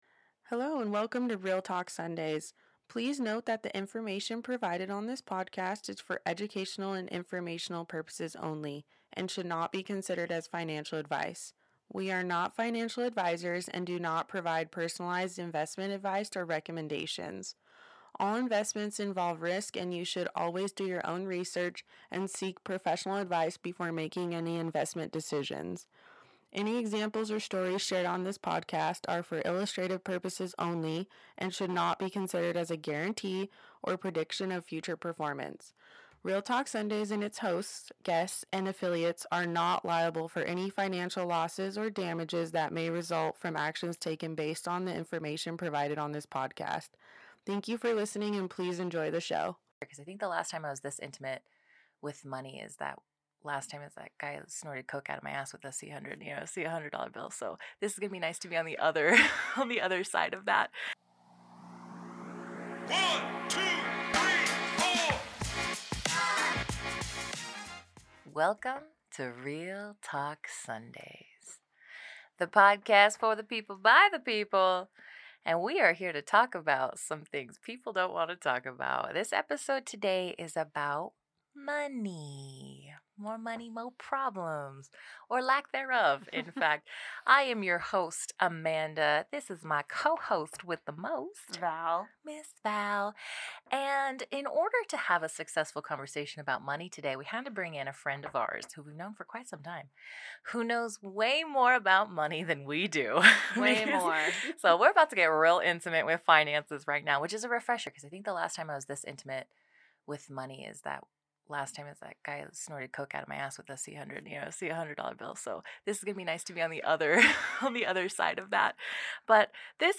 Come along as we explore the ways in which our emotions and past experiences can influence our financial decision-making and, ultimately, impact our ability to save. Our hosts share personal stories and insights into their own journeys with money.
Our guest speaker offers practical tips on how to reframe our relationship with money and make more conscious and intentional financial choices.